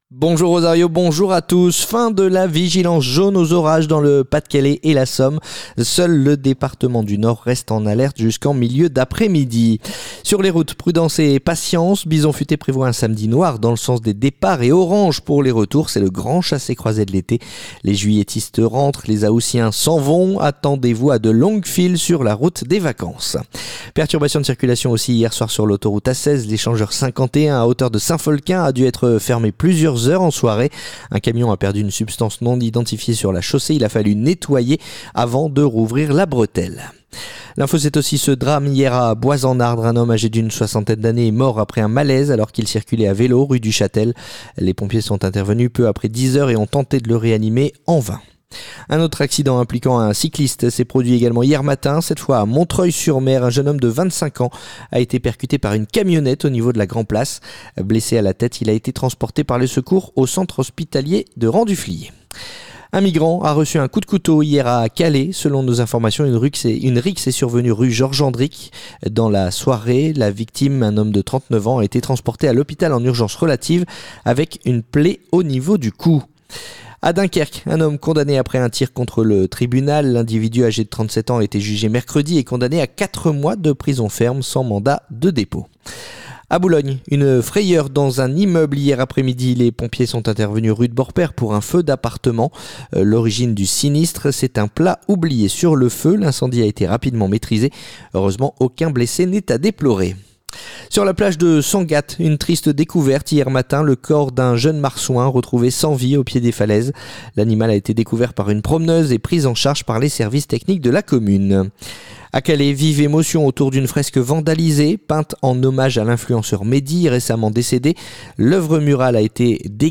Le journal du samedi 02 août